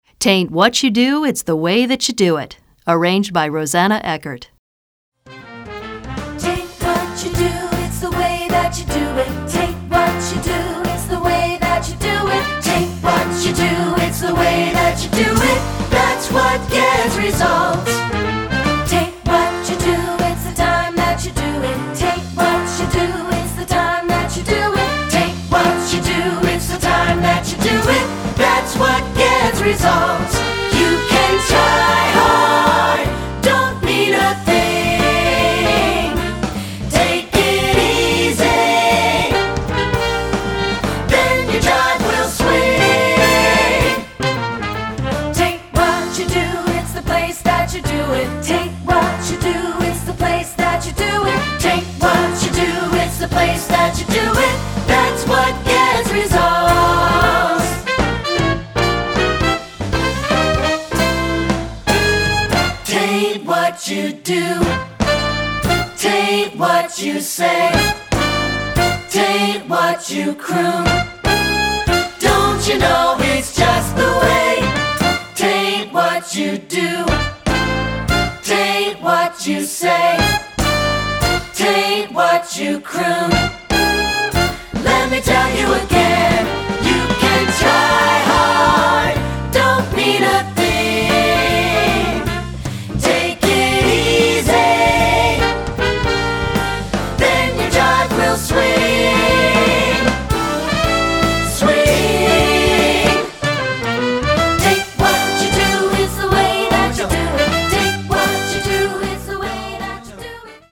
Choral Jazz